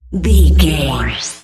Sound Effects
funny
magical
mystical
special sound effects